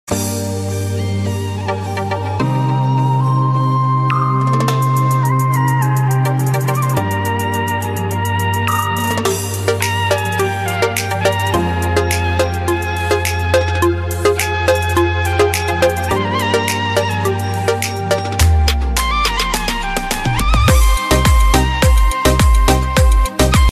Sad & Relaxing Tunes for Your Phone